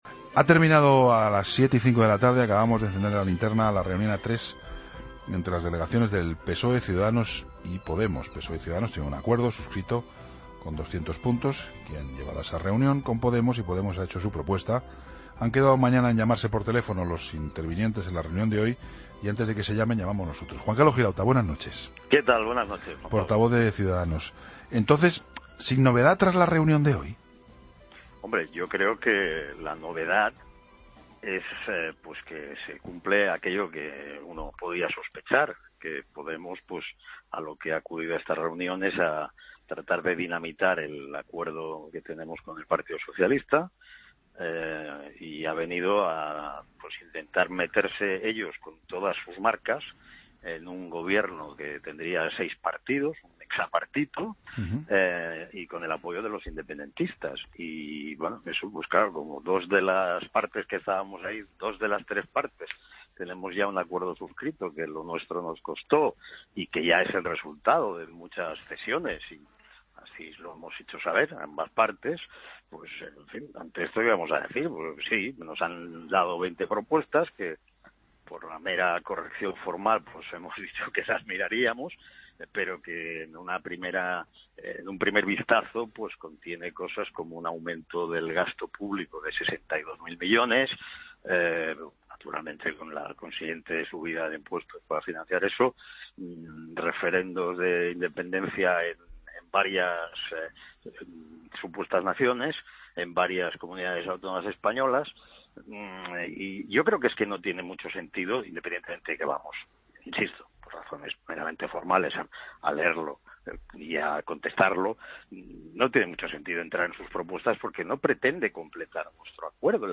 AUDIO: Escucha la entrevista al portavoz de Ciudadanos en el Congreso, Juan Carlos Girauta, en 'La Linterna'